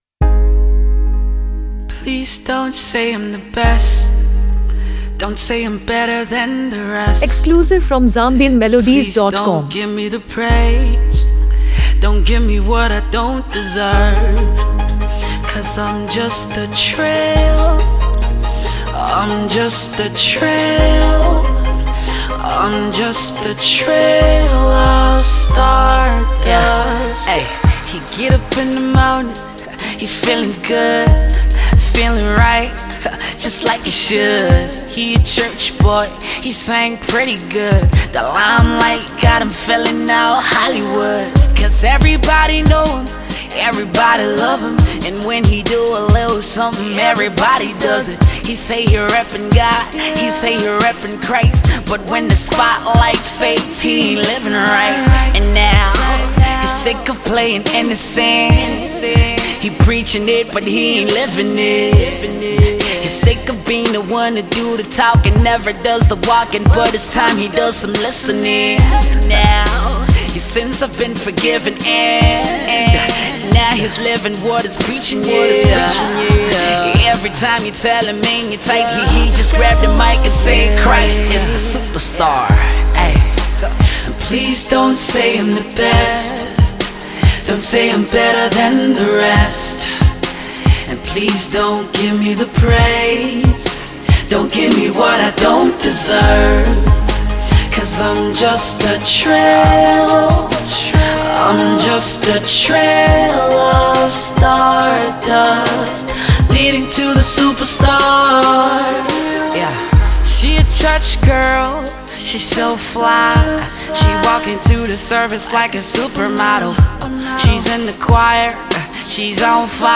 Known for blending Afro-soul, R&B, and contemporary pop
smooth vocals